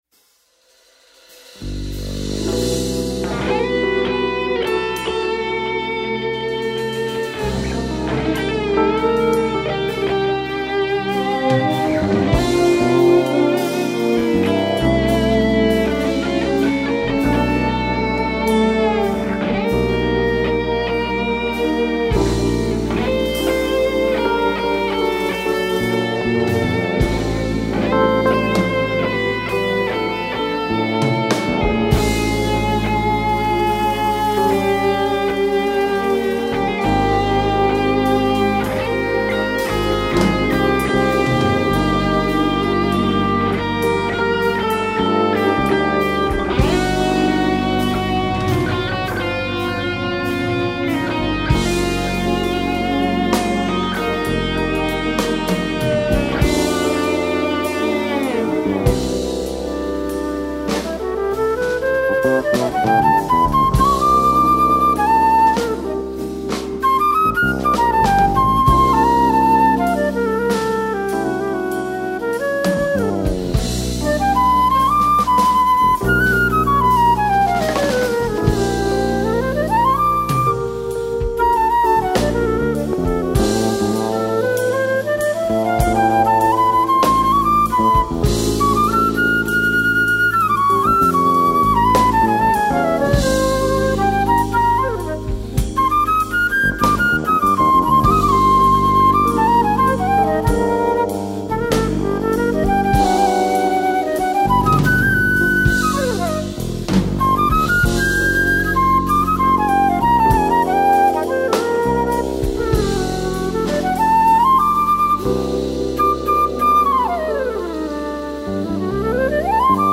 1178   06:07:00   Faixa:     Jazz